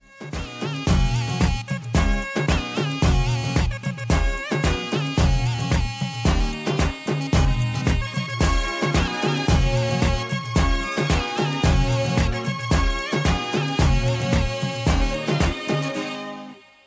bollywood songs ringtones